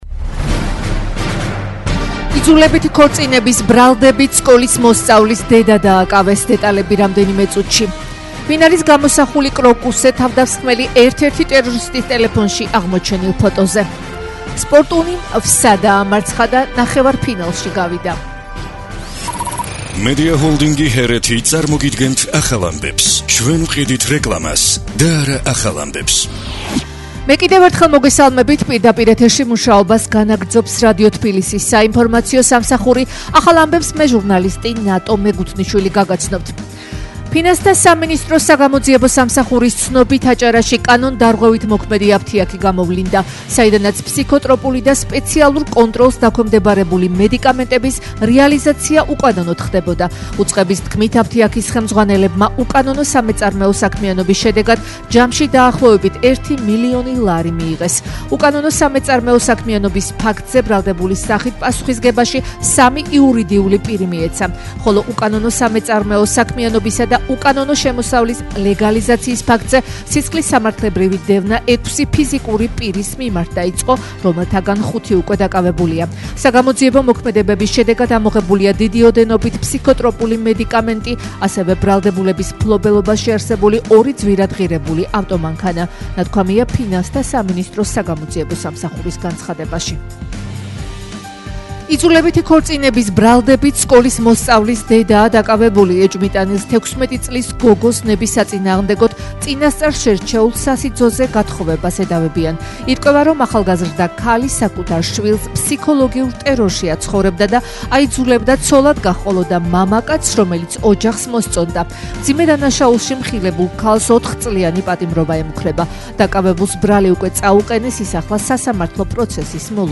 ახალი ამბები11:00 საათზე